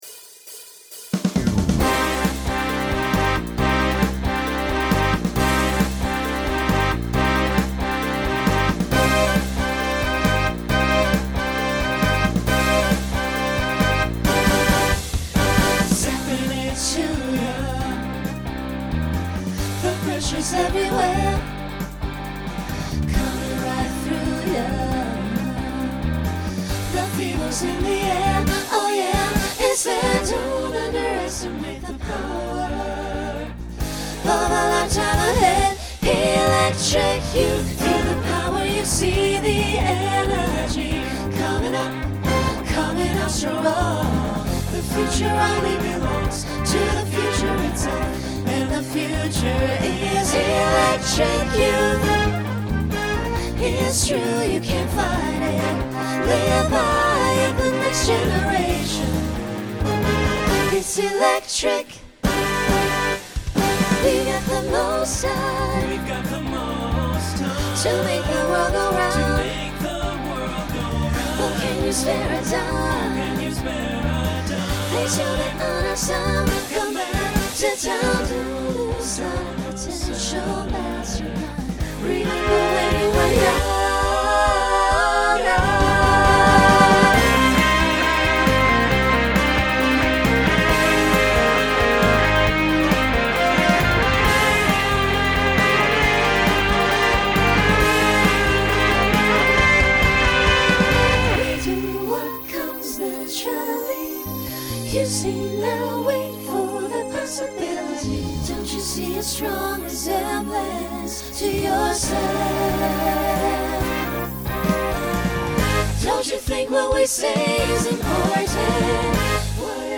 Genre Pop/Dance Instrumental combo
Voicing SATB